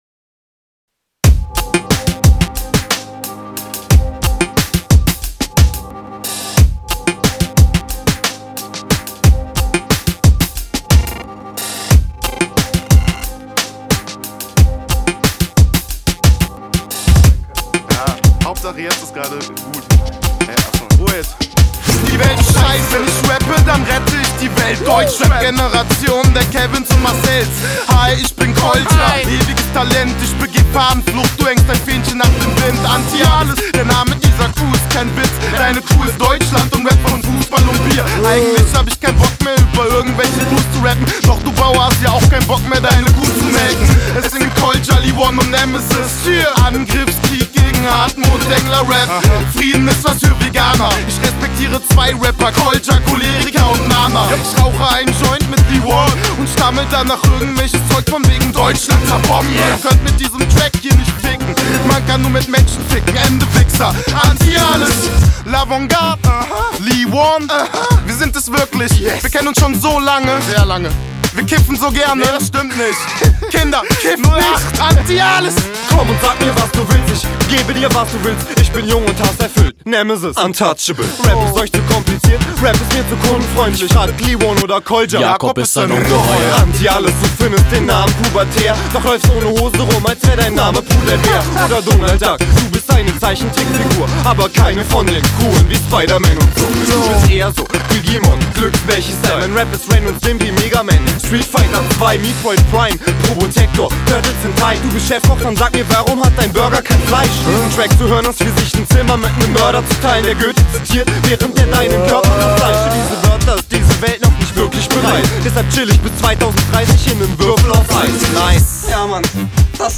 Rap von unserem "local Hero"